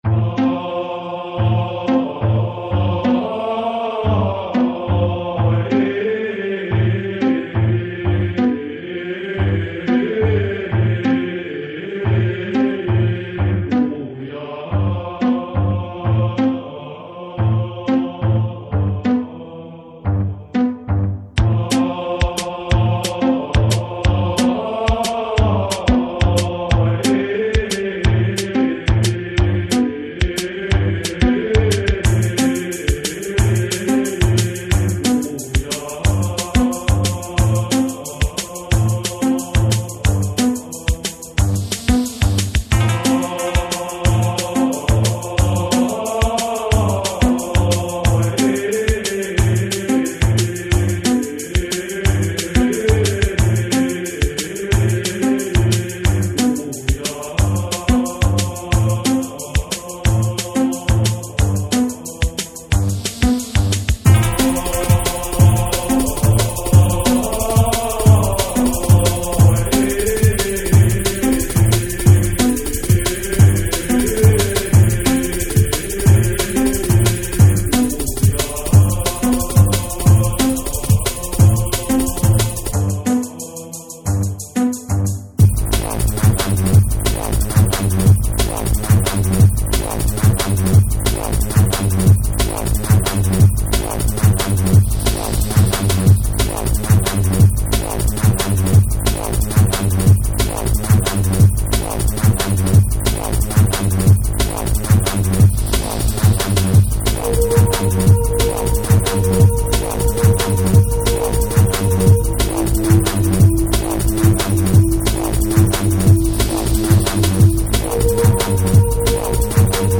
drum'n'bass
Here are MP3 files featuring our tracks and livesets: